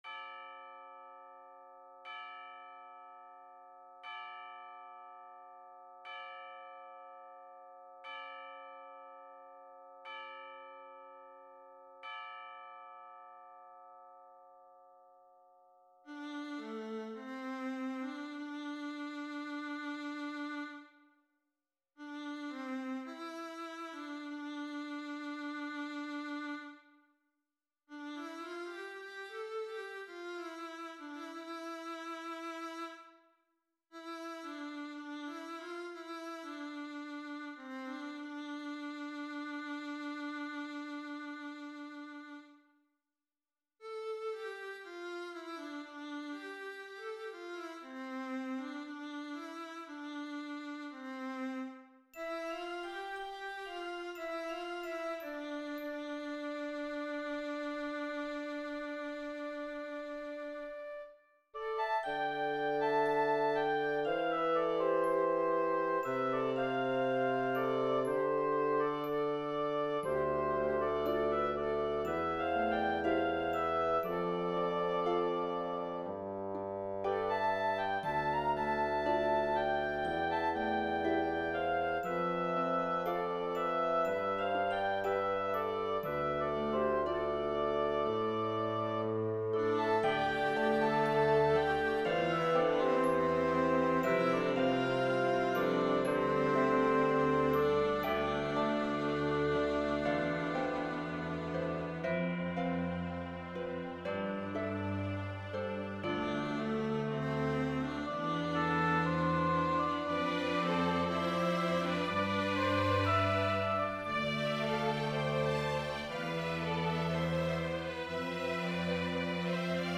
Sanctus Gregorian chant tune on which “Sanctuary” is based
III. Sanctuary (MIDI-rendered recording)